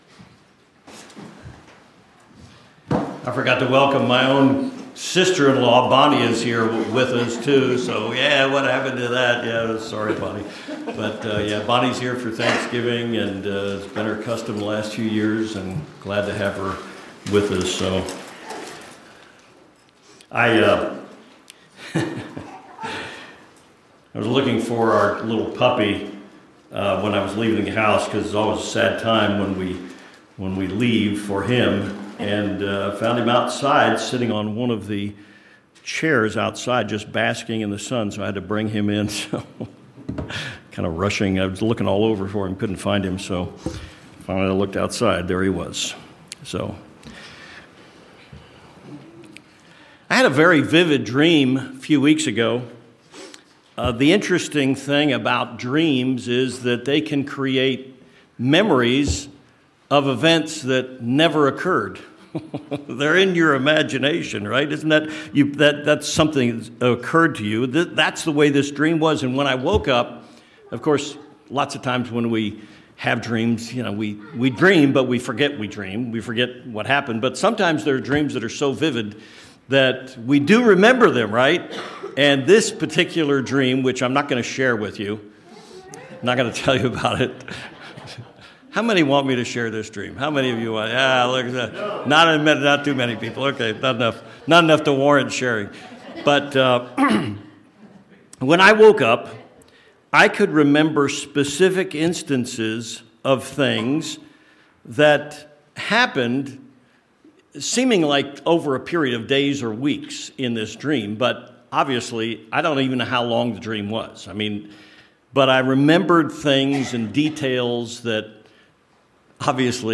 Being vigilant is a priority for the Church of God during tumultuous times. This sermon shows several keys to understanding the world we live in and how to remain awake spiritually.